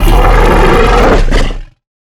giant_aggressive_0.ogg